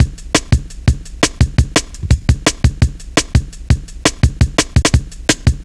Index of /90_sSampleCDs/Zero-G - Total Drum Bass/Drumloops - 3/track 57 (170bpm)